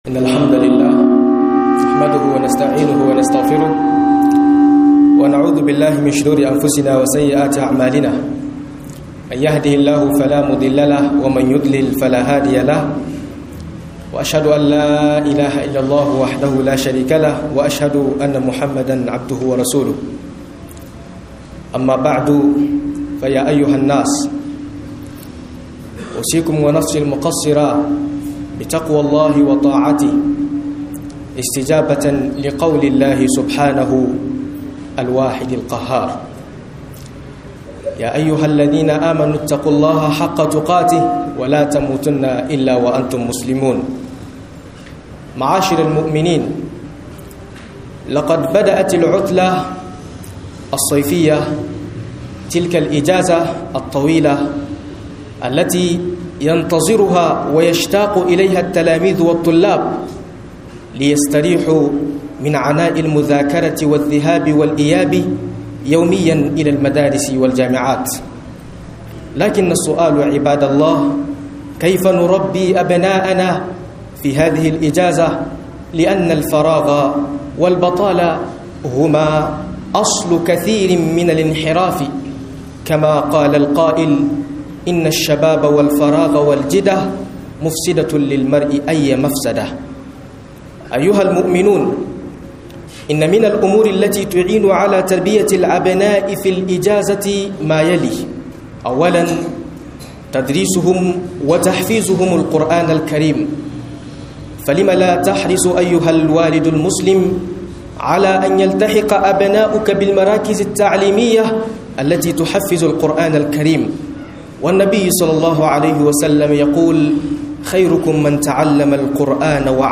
Tarbiyyar yara a vacance - MUHADARA